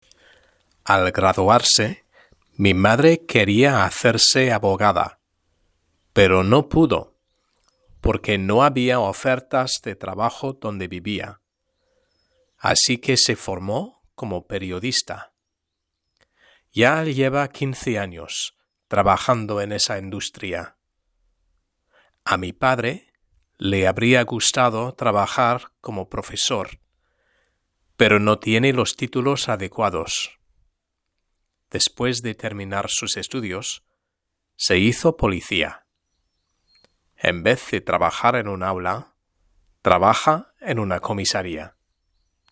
Lectura en voz alta: 1.3 La educación y el trabajo #3